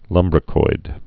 (lŭmbrĭ-koid)